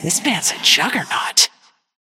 Haze voice line - This man's a juggernaut!